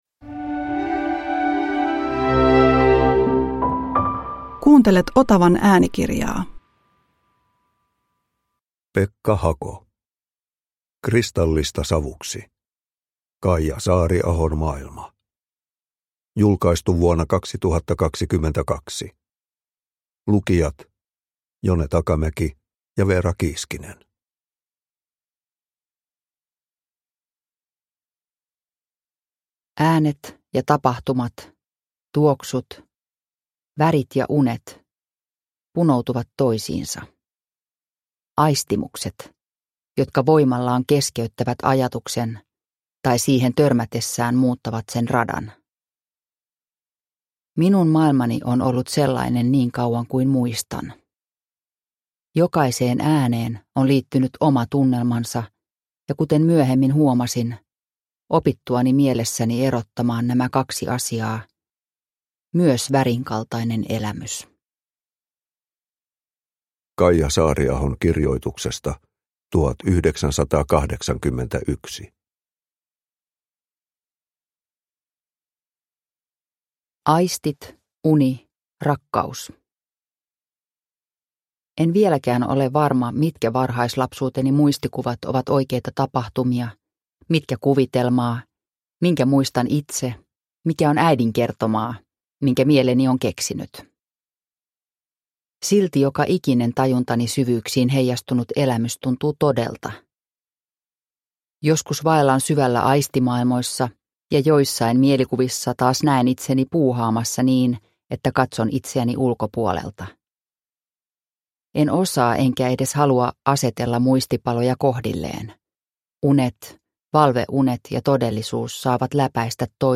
Kristallista savuksi – Ljudbok – Laddas ner